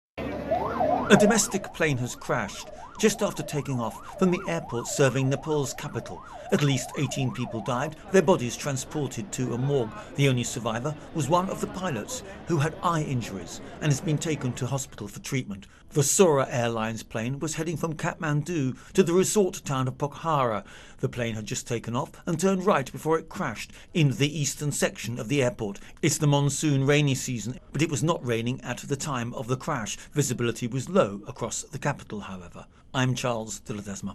((Begins with aftermath sound))